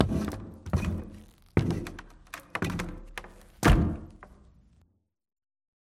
amb_fs_stumble_wood_14.mp3